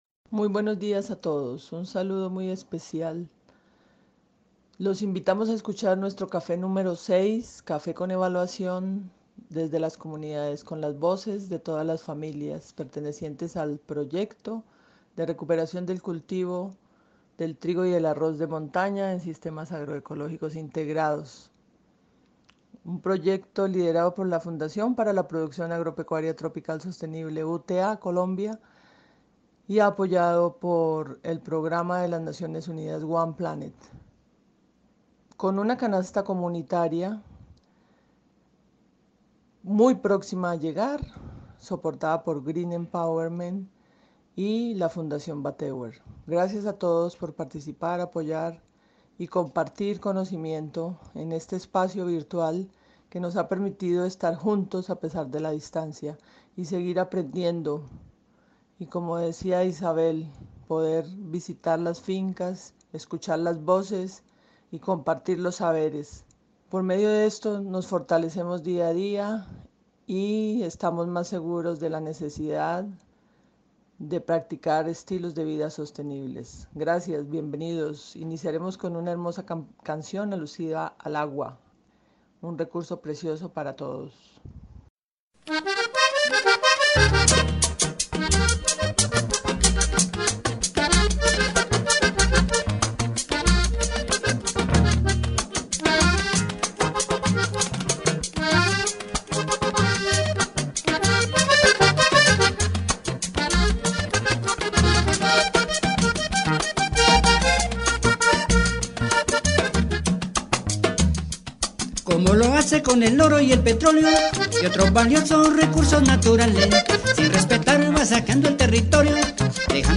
III Escuela de Estilos de Vida Sostenible  6° Café Con Evaluación  Un saludo muy especial  Estos audios de evaluación mostraron el compromiso y la fuerza con que las familias del proyecto han sentido la participación en el proceso de las Escuelas de Estilo de Vida Sostenible 3 y 4 virtuales debido a la situación de pandemia que estamos viviendo. Son escuelas trabajadas vía celular, Waths App en línea, sin conexión de internet, sin plataformas especiales, pues es muy difícil en las zonas rurales de montaña donde vivimos las familias del proyecto.